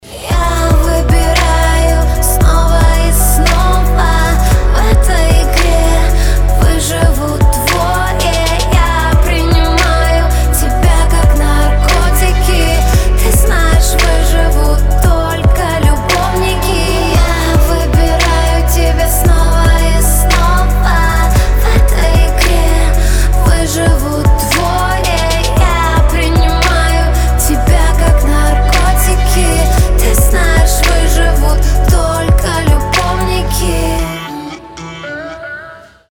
поп
женский вокал
чувственные